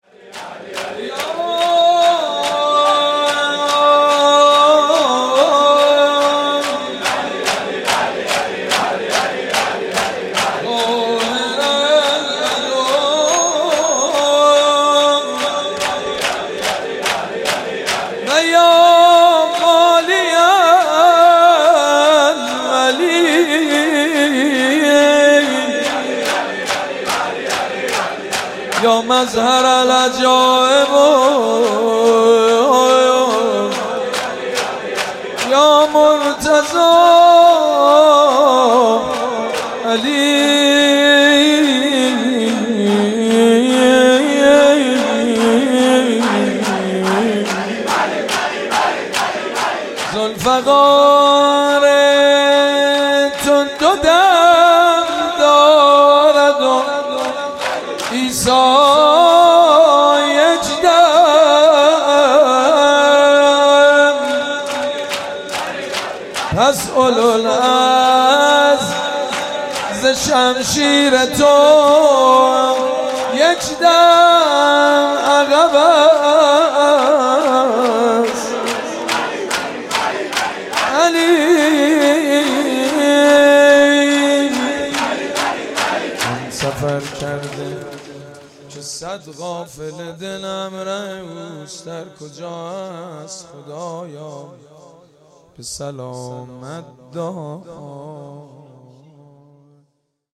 یا قاهرالعدو و یا والی الولی | نوا